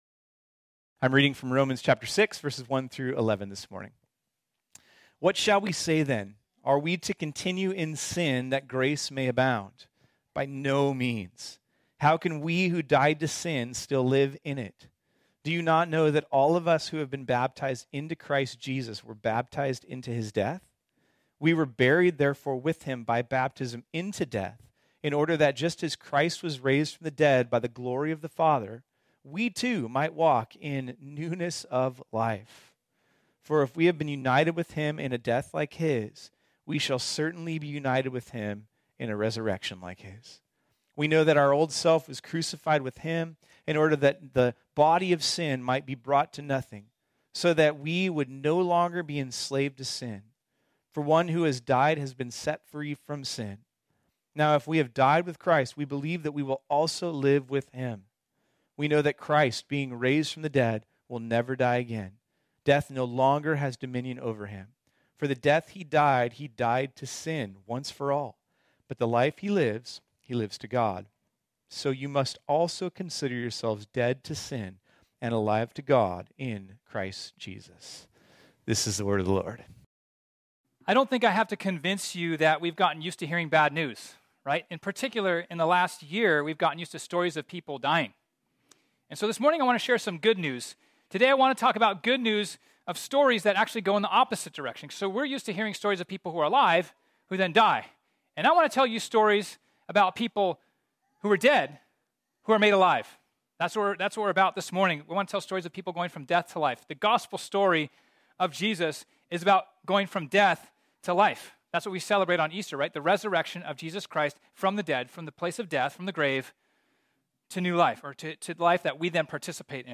This sermon was originally preached on Sunday, April 4, 2021.